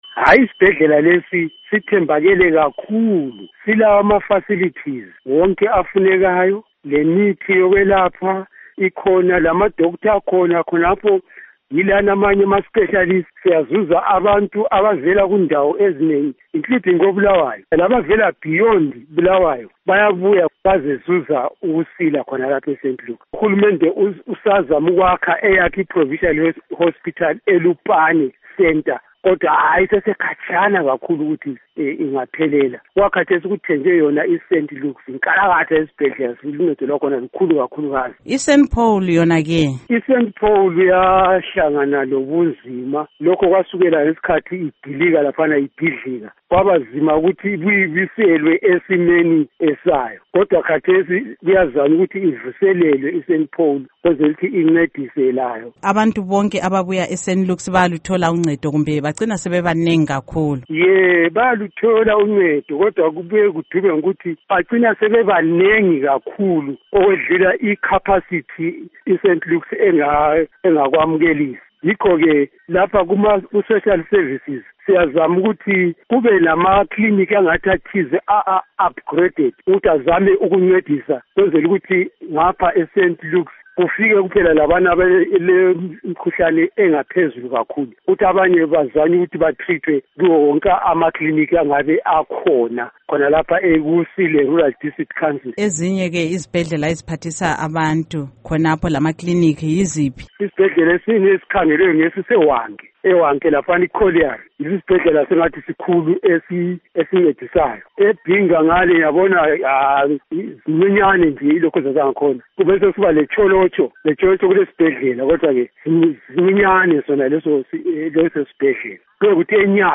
Ingxoxo LoKhansila Esau Sibanda